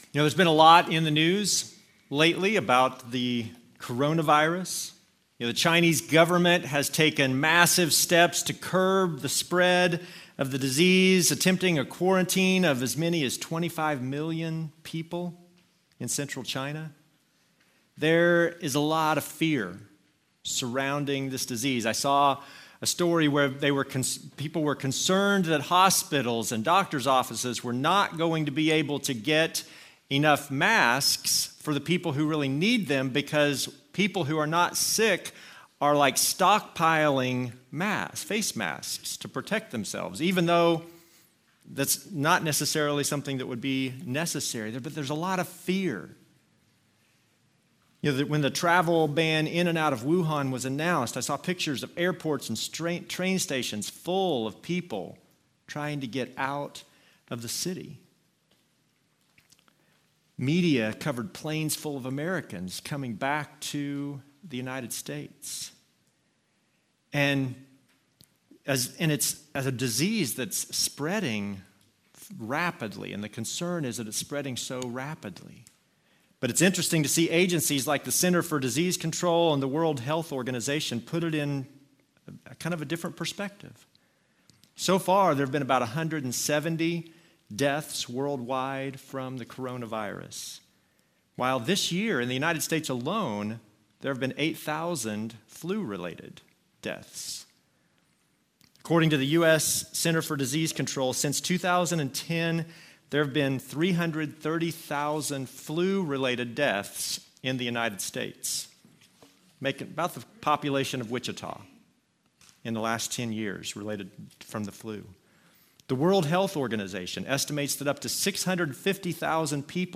Passage: Revelation 8 Service Type: Normal service